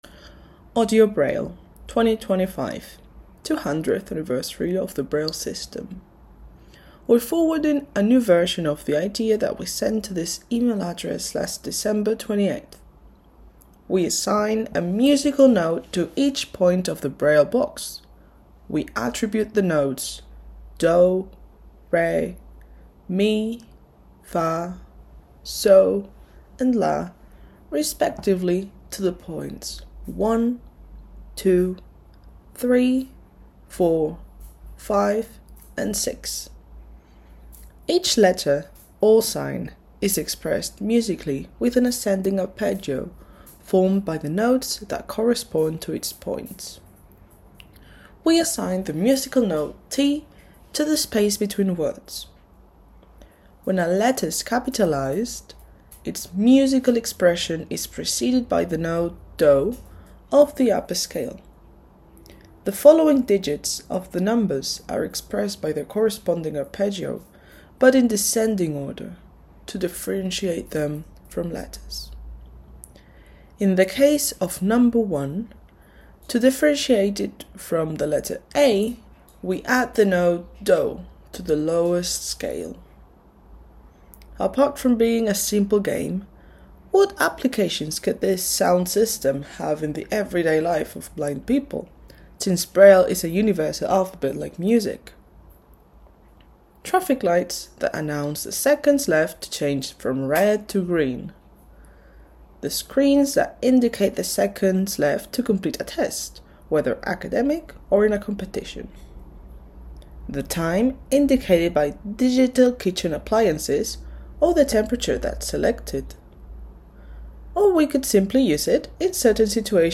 A big thank you for your dedication—especially for bringing us this fully auditory version of today’s contribution!